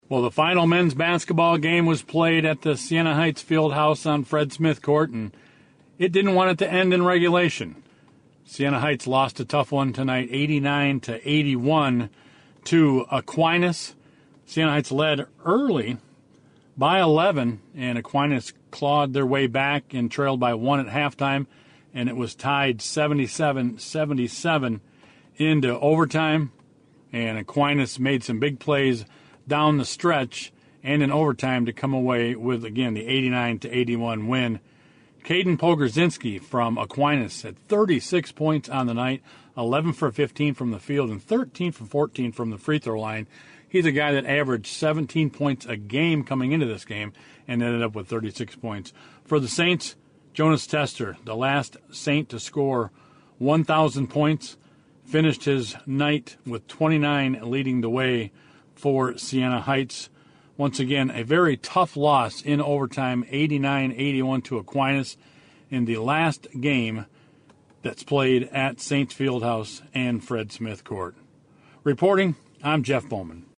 broadcast the men’s game…